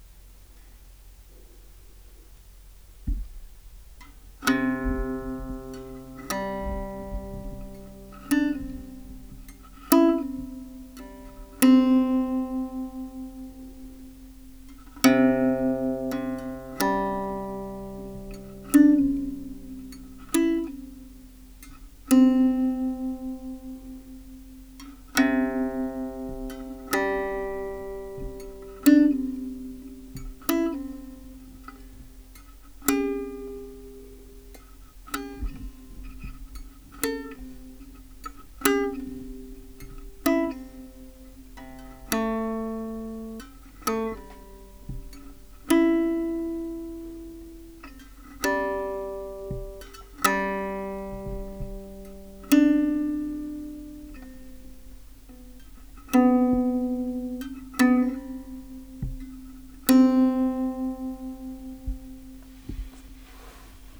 三味線・音源
音節：春（春灯回廊） 天候：雨 駒：象牙（オリジナル）